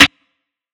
Snare [29].wav